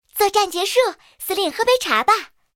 SU-26战斗返回语音.OGG